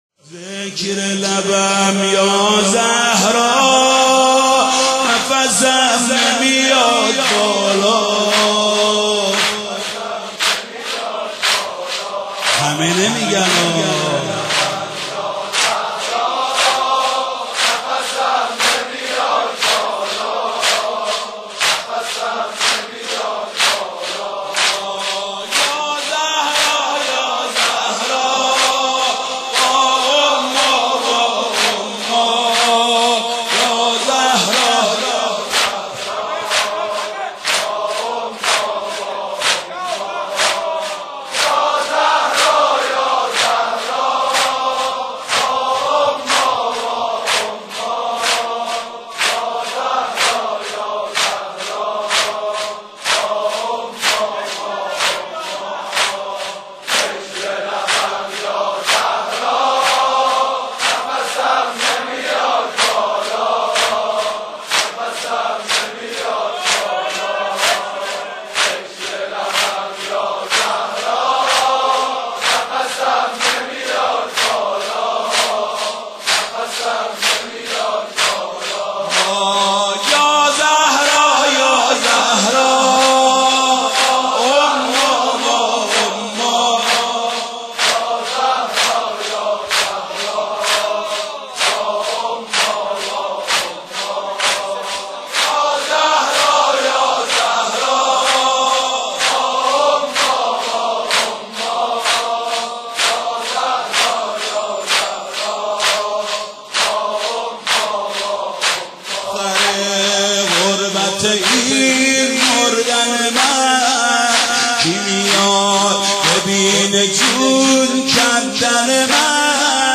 گل زیبای یامال به الچه روی پاس دیدنی بالده / فیلم برچسب‌ها: مداحی حاج محمود کریمی دیدگاه‌ها (5 دیدگاه) برای ارسال دیدگاه وارد شوید.